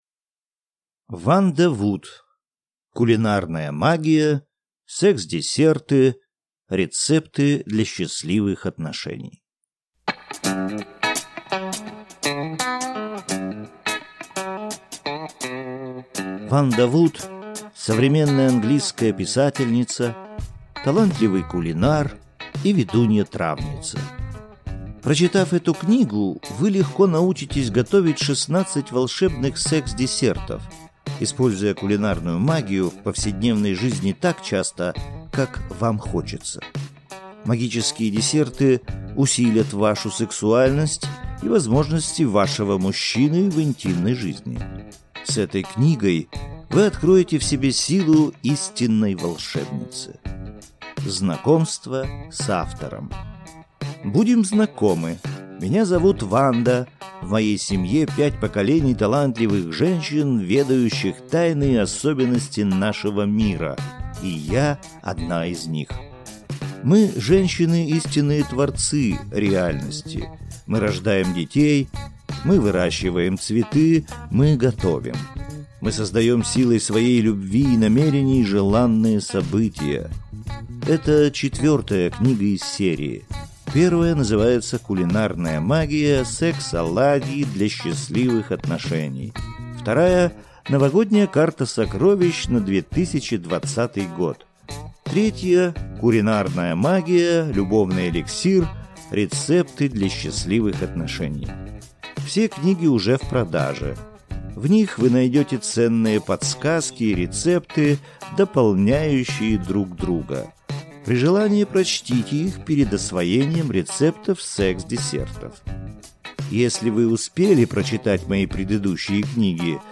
Аудиокнига Кулинарная магия. Секс-десерты. Рецепты для счастливых отношений | Библиотека аудиокниг